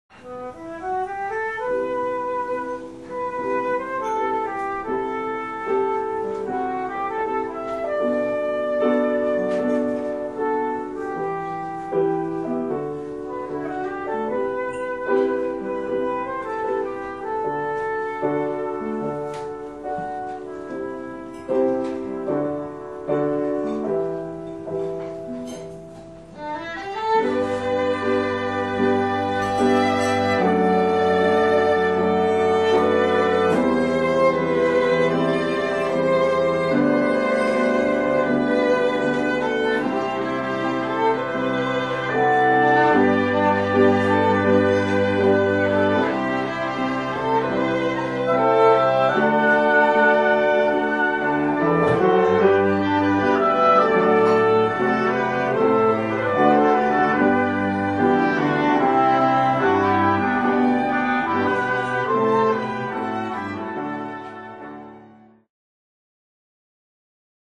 Flute、Oboe、Clarinet、Violin、Cello、Piano